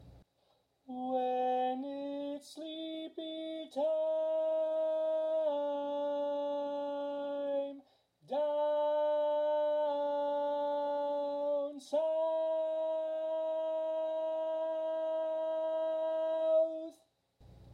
Key written in: C Major
Type: Barbershop
Each recording below is single part only.